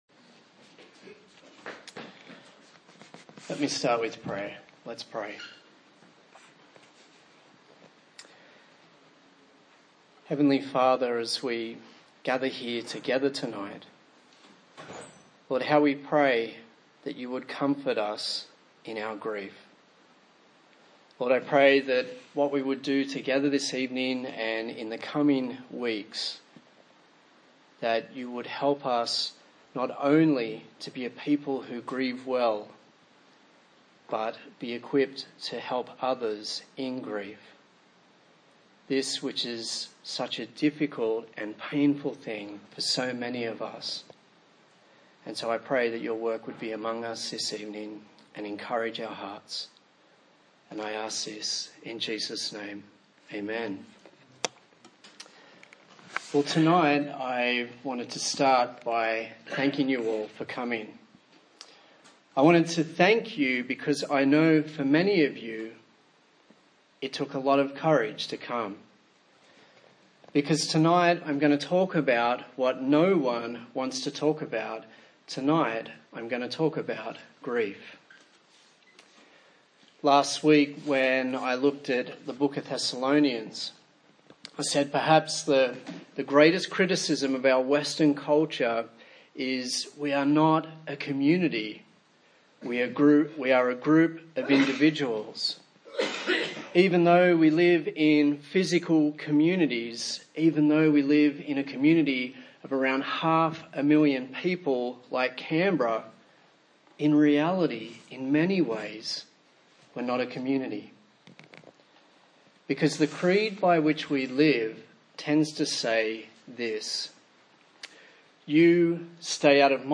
A sermon in the series on Grief
Psalm 23 Service Type: TPC@5 A sermon in the series on Grief